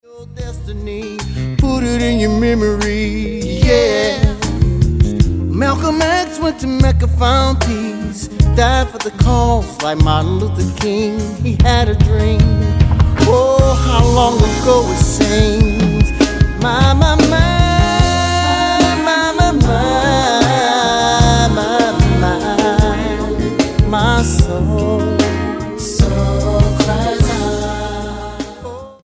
STYLE: R&B
old-style production